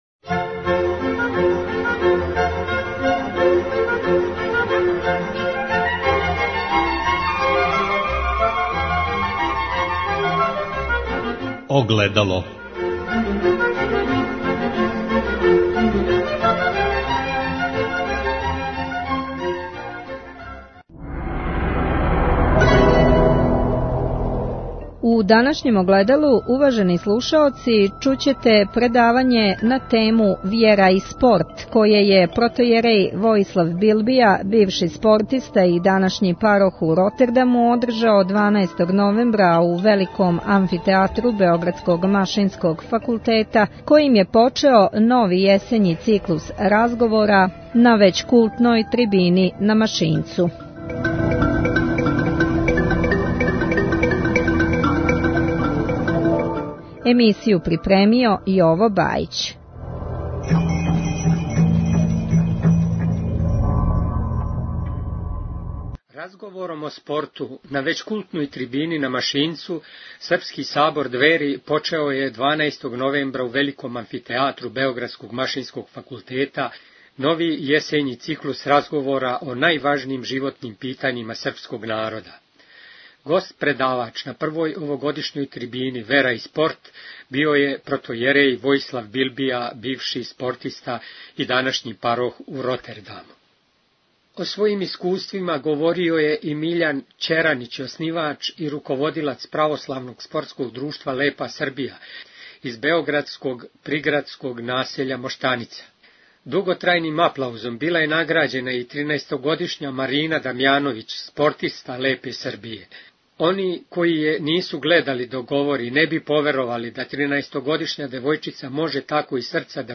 Разговором о спорту, на већ култној Трибини на Машинцу, Српски сабор Двери почео је 12. новембра у великом амфитеатру београдског Машинског факултета нови јесењи циклус разговора о најважнијим животним питањима српског народа.